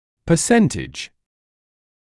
[pə’sentɪʤ][пэ’сэнтидж]процент; процентное содержание; часть, доля